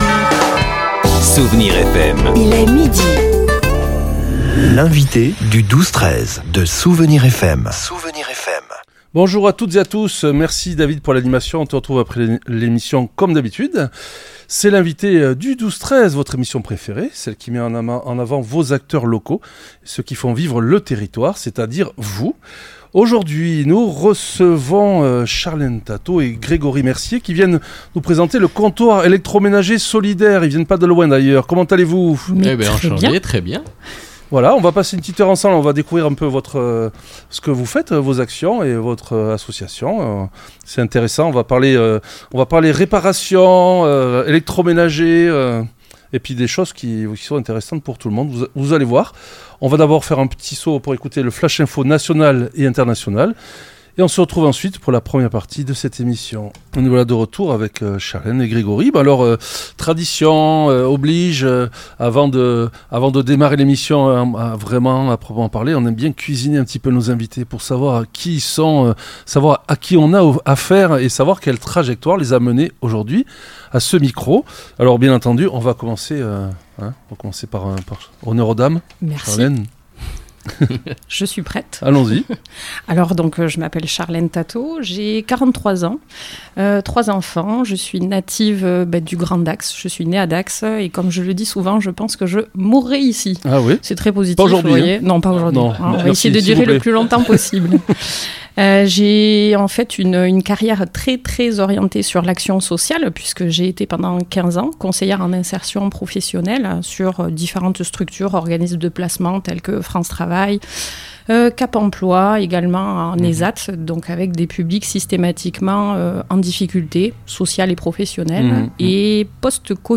L'entretien a permis de découvrir les multiples facettes de leur action, notamment leur rôle de Relais Numérique Emmaüs Connect.